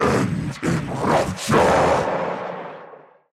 vruunboss_warcry_end_01.ogg